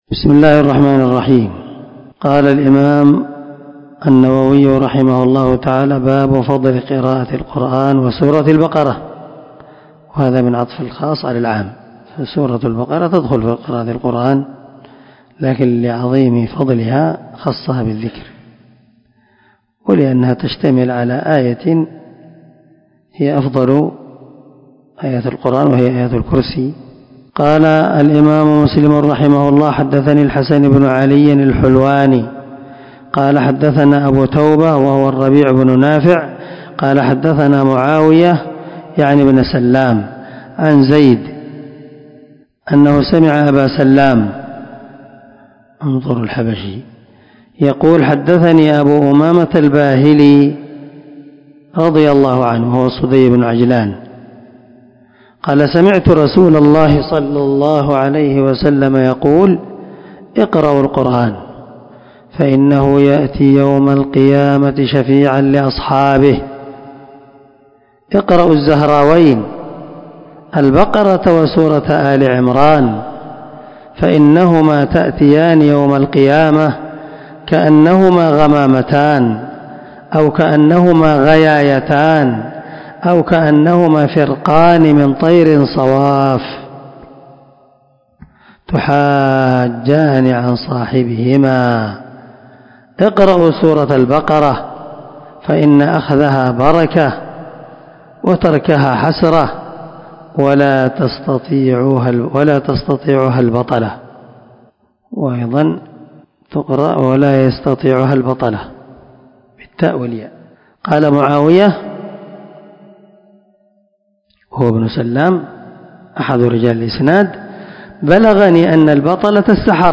487الدرس 55 من شرح كتاب صلاة المسافر وقصرها حديث رقم ( 804 ) من صحيح مسلم
دار الحديث- المَحاوِلة- الصبيحة.